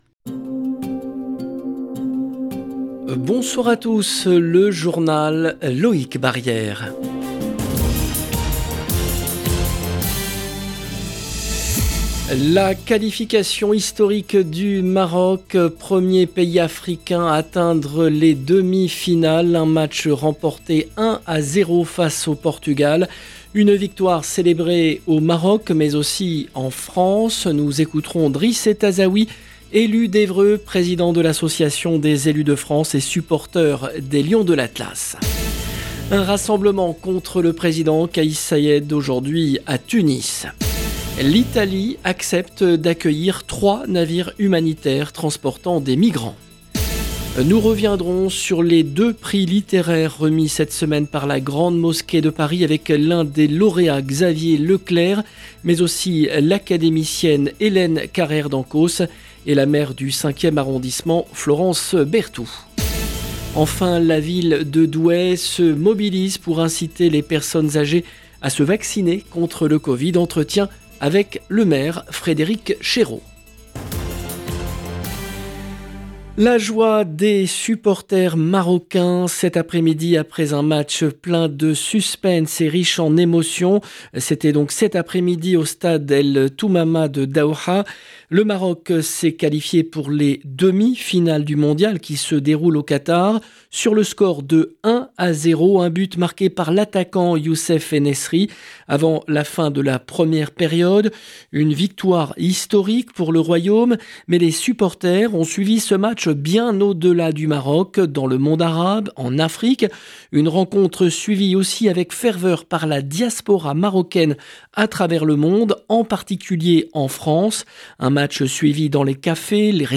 Enfin la ville de Douai se mobilise pour inciter les personnes âgées à se vacciner contre le covid. Entretien avec le maire, Frédéric Chéreau. 0:00 19 min 41 sec